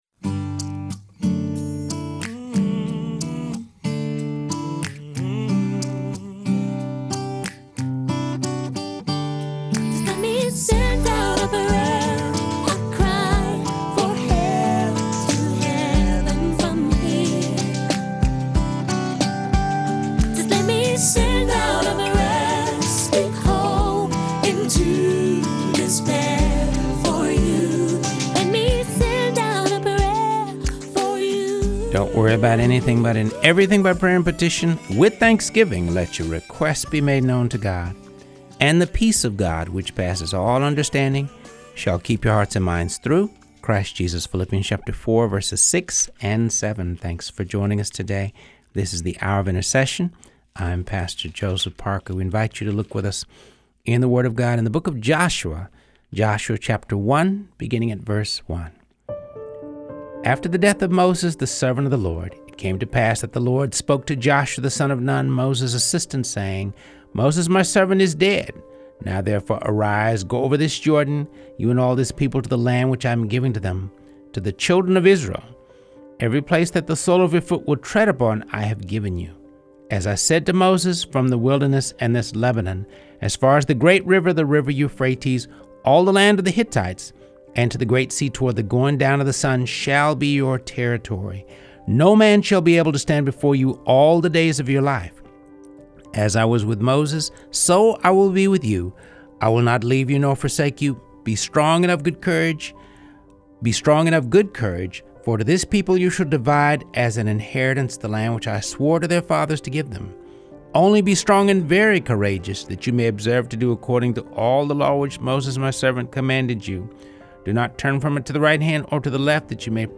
continues his project of reading through the Bible.